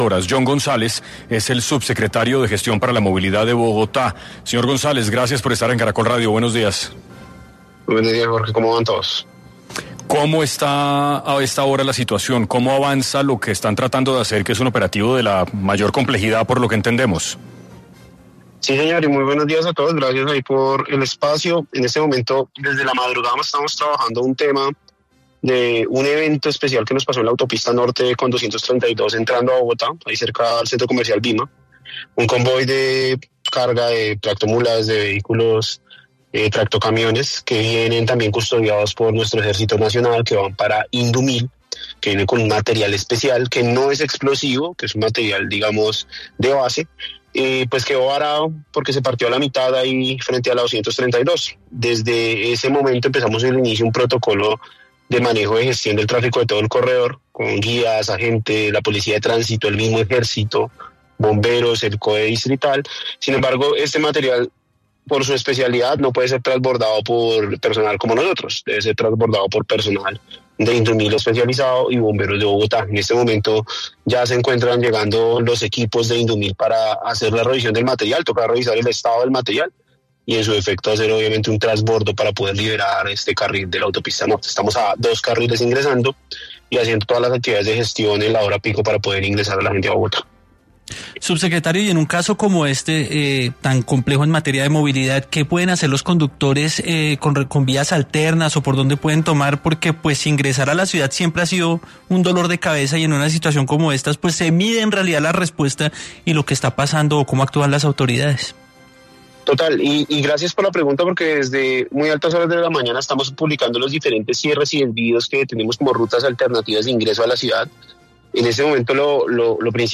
Jhon González, Subsecretario de Gestión para la Movilidad, se refirió a la contingencia por un tractocamión que transportaba cerca de 30 toneladas de un insumo militar, en sentido Chía - Bogotá.
Jhon González, Subsecretario de Gestión para la Movilidad, explicó en 6AM de Caracol Radio la magnitud del operativo que se ha desplegado para manejar la situación.